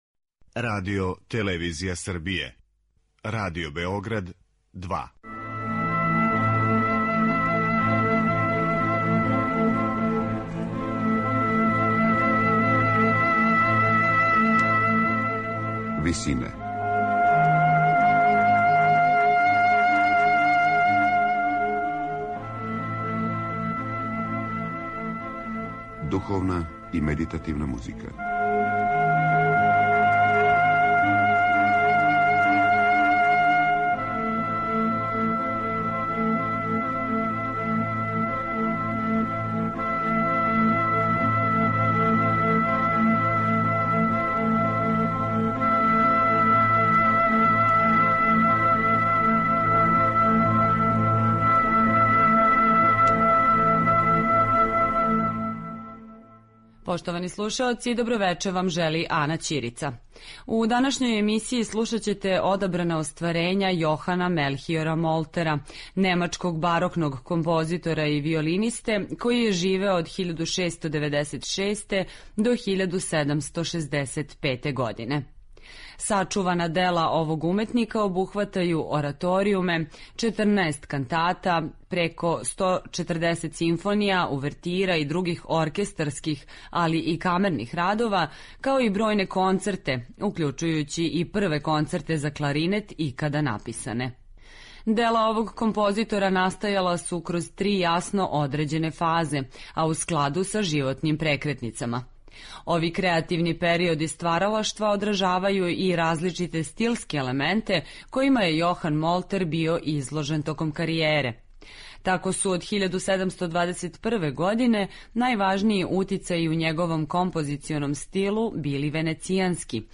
Кантате